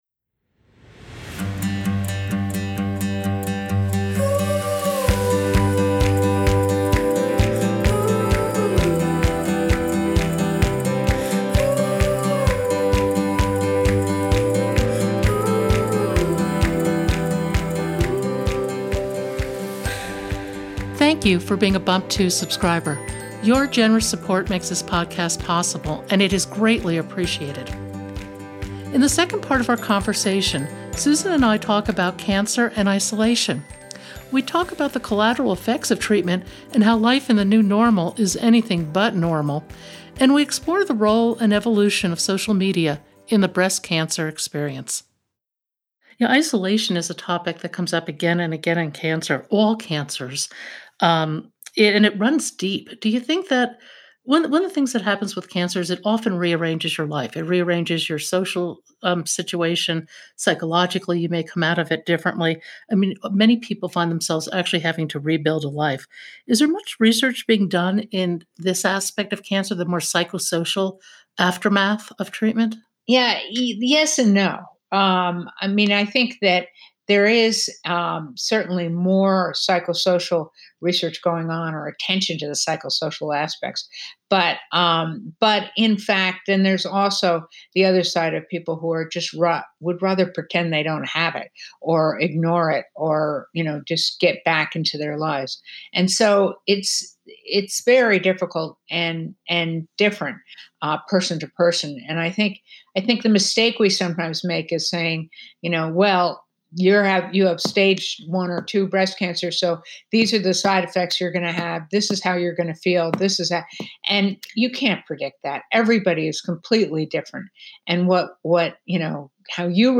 Susan Love is a world renown breast cancer surgeon who's Foundation is changing the way we view and perhaps treat breast cancer. Join us for a fascinating conversation from a leading surgeon and cancer survivor.